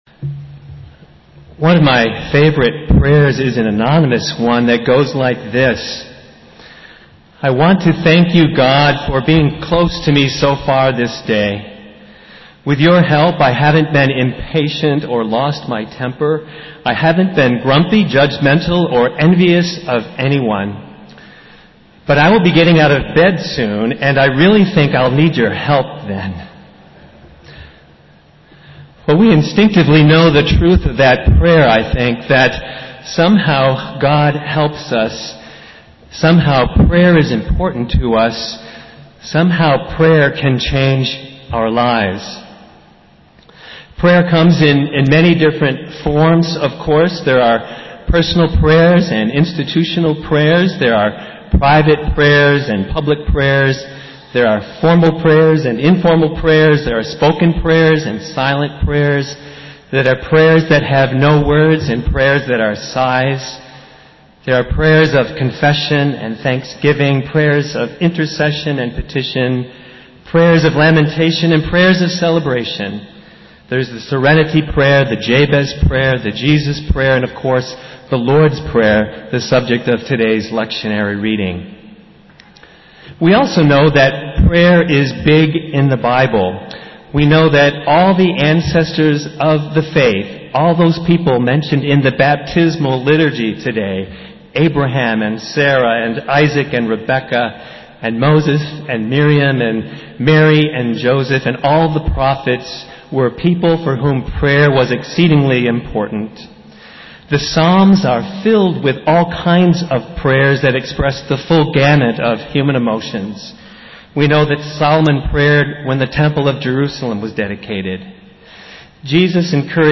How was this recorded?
Festival Worship - Ninth Sunday after Pentecost